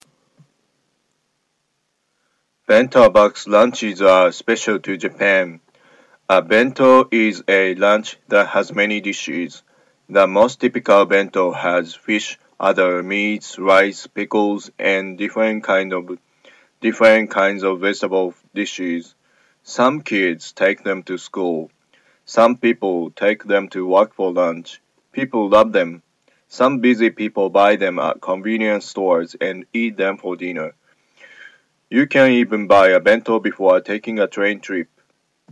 英語喉のBento Box Sentence を読みました。